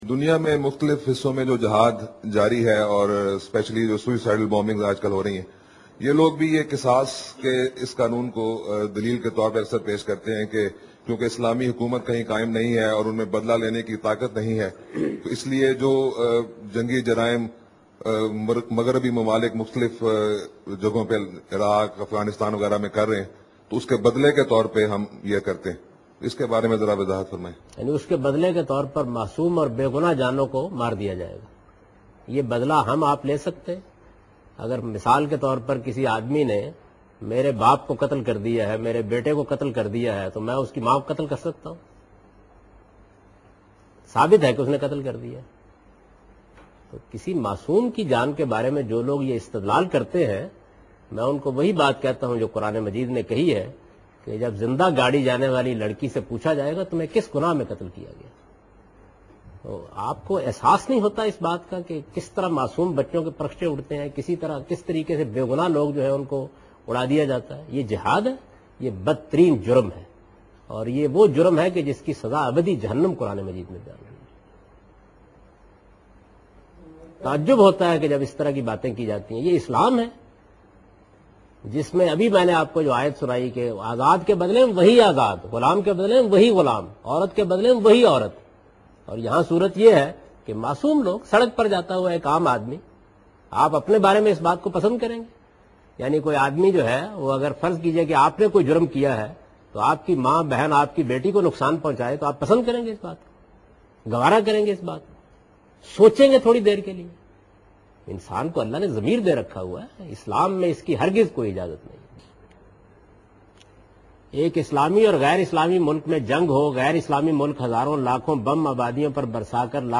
Category: Reflections / Questions_Answers /